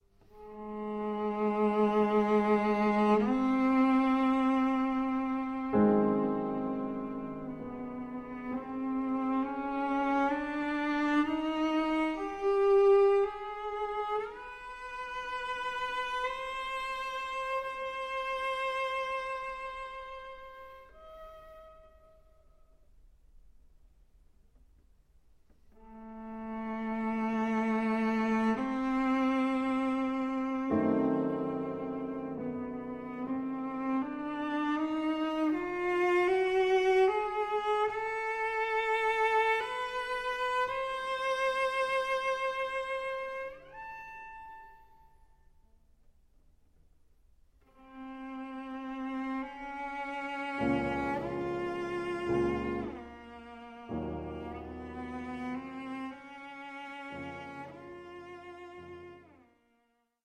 Works for cello & piano